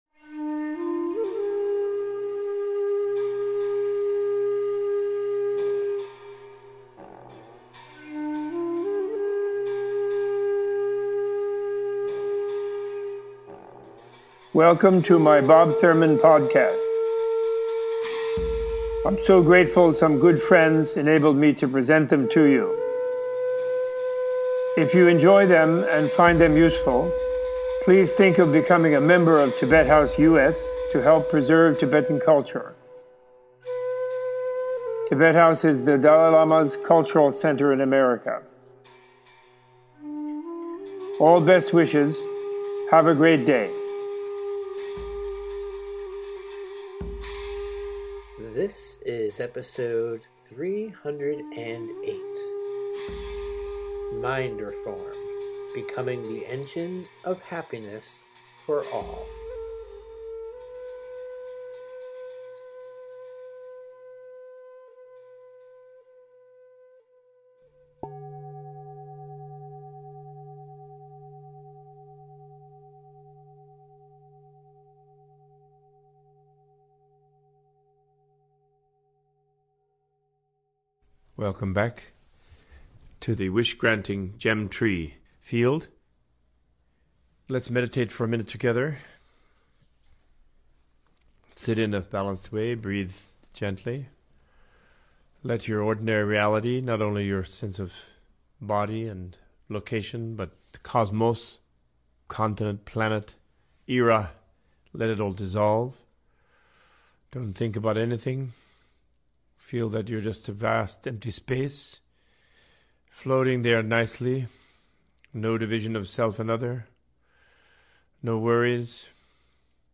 Opening with a guided "Seven Limbs of Practice" meditation Robert Thurman in this episode gives an all levels teaching on the Four Noble Truths and the transformational practice of the exchange of self for others or Lojong. Episode includes an explanation of the yoga of positive evolutionary action and how acting transcendentally and shift our understanding of reality and emotional interrelationship with all beings, friend, foe, and neutral ones.